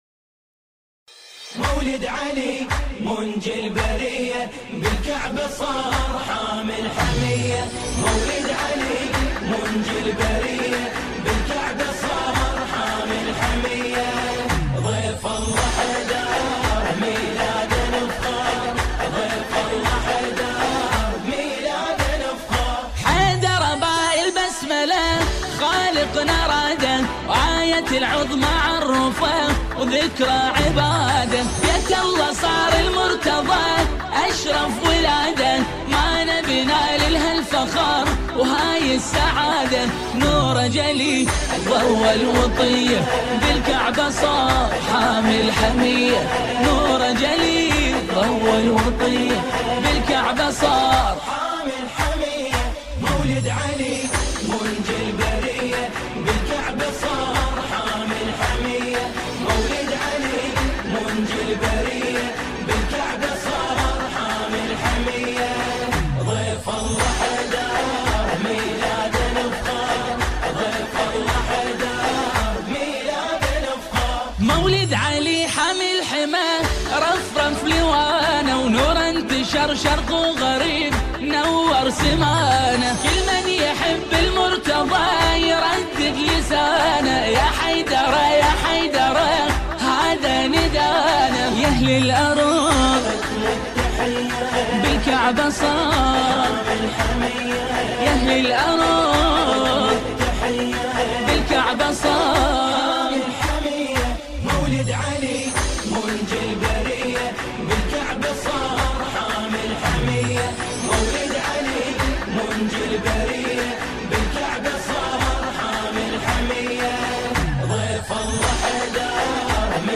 گروهی از جمعخوانان هستند